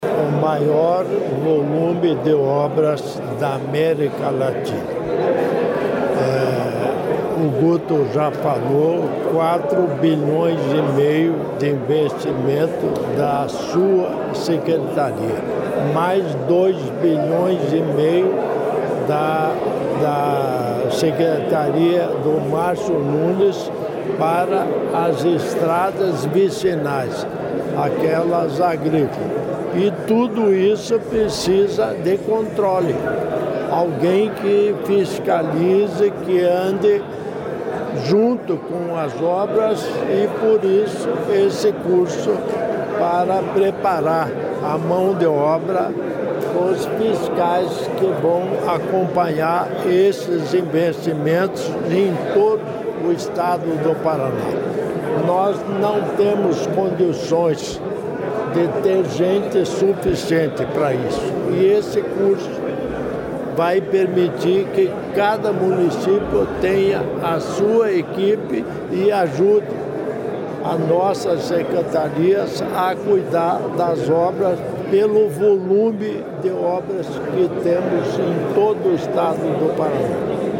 Sonora do governador em exercício Darci Piana sobre o projeto Embaixadores do Asfalto